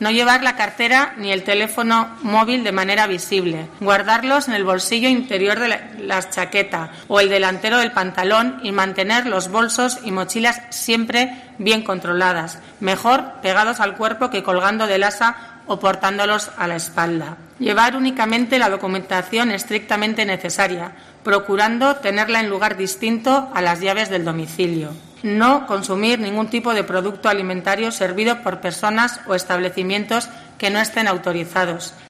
Amaia Arregi, Concejala del Área de Seguridad